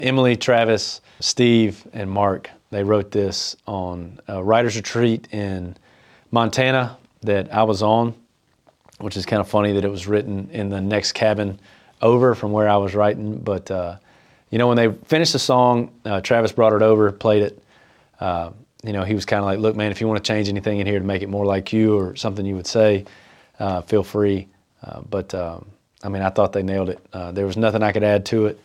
Jordan Davis takes us behind the scenes of how his song “I Ain’t Sayin'” came to life during a writers’ retreat.